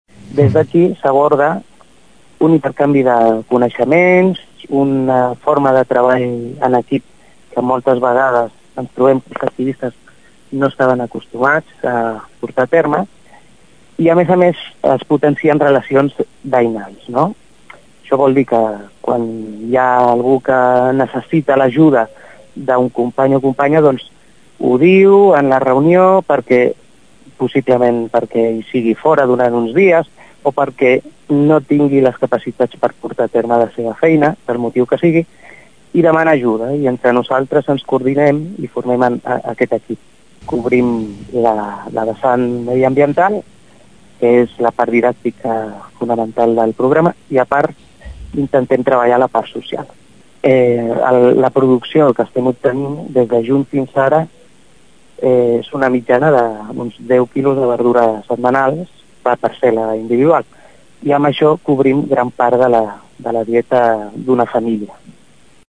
Ho explica en declaracions a Ràdio Tordera